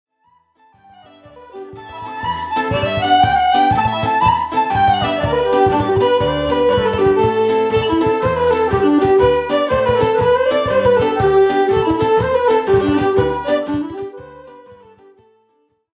who played Celtic, French-Canadian and original music